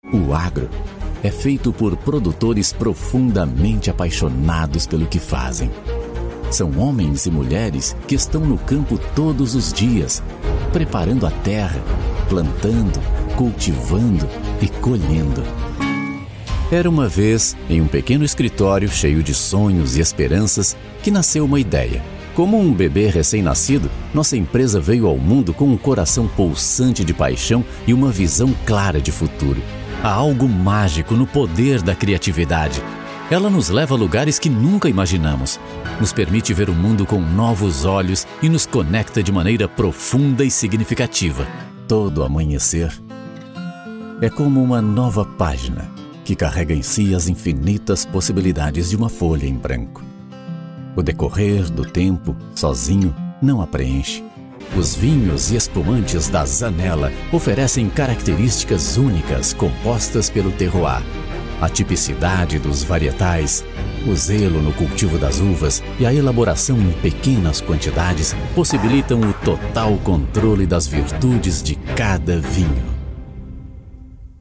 Male
Narration
Voz grave, natural e conversada, suave e clássico
BaritoneBassDeepLow